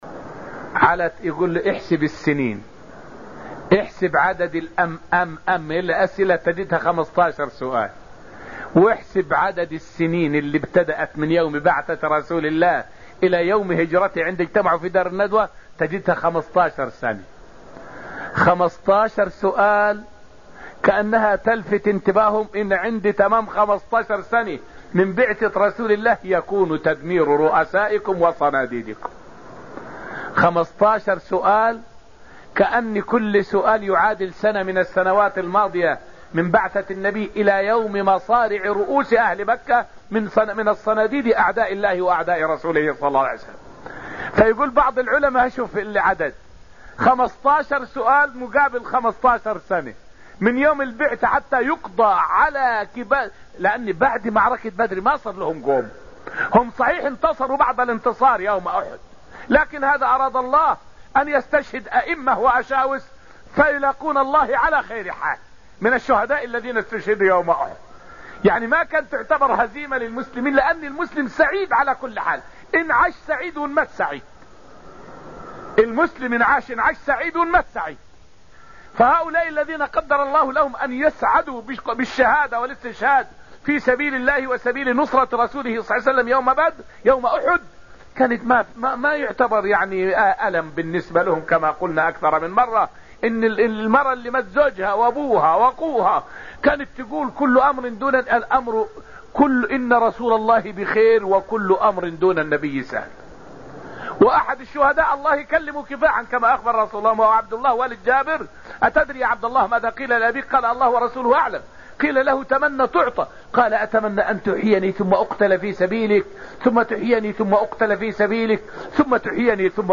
فائدة من الدرس السابع من دروس تفسير سورة الطور والتي ألقيت في المسجد النبوي الشريف حول لطيفة: خمسة عشر سؤالًا وخمسة عشر عامًا.